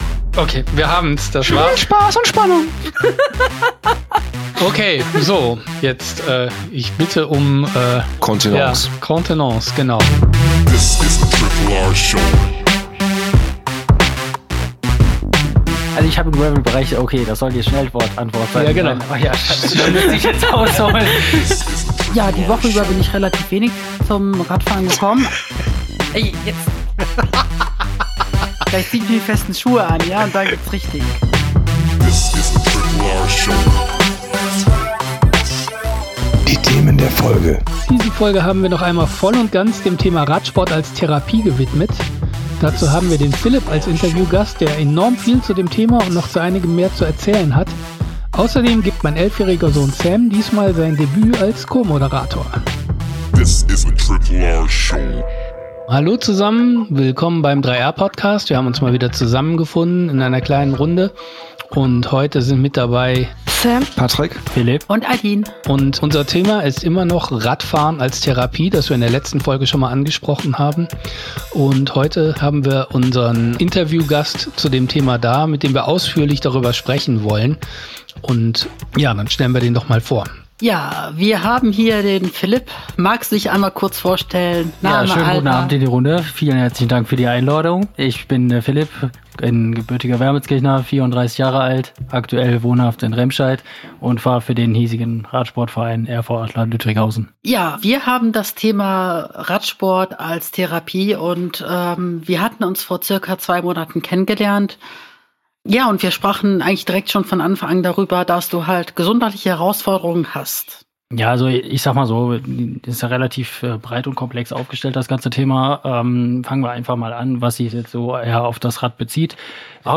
Radfahren als Therapie– Diskussion der 3R-Crew Themen der Folge